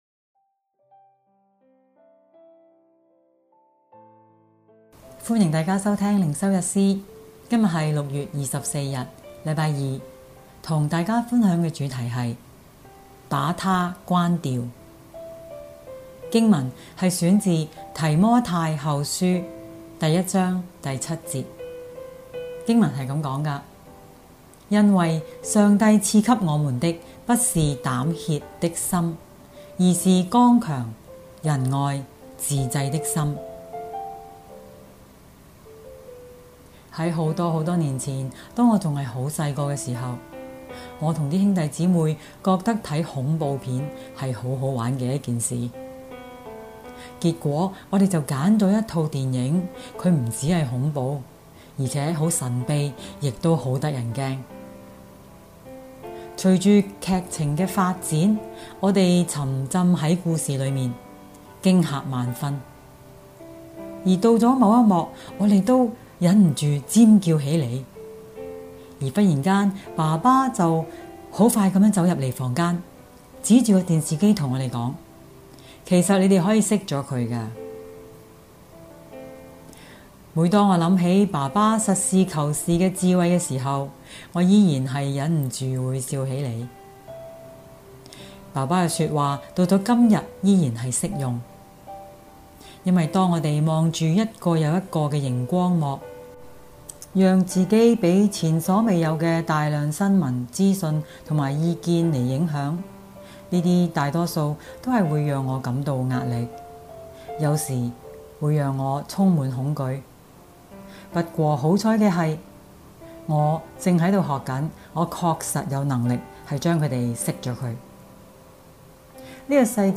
循道衞理聯合教會香港堂 · 錄音佈道組 Methodist Outreach Programme